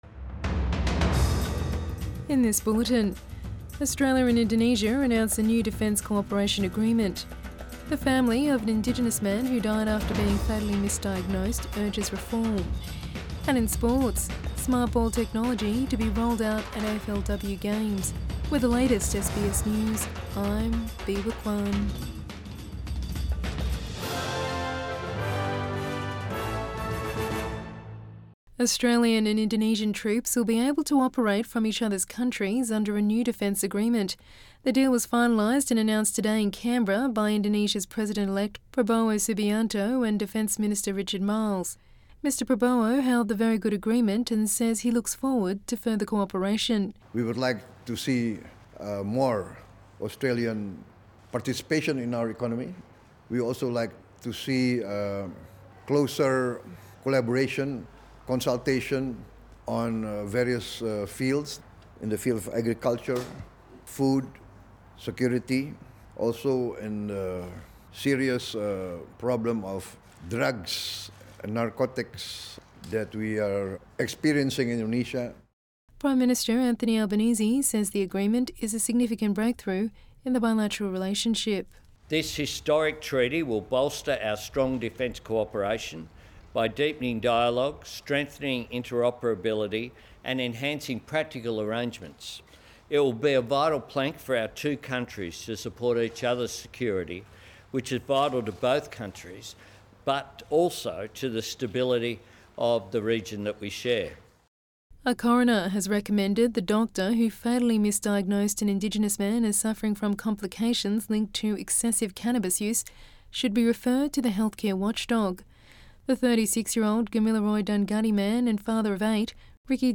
Evening News Bulletin 20 August 2024